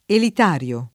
elitario [ elit # r L o ]